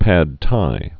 (păd tī, päd)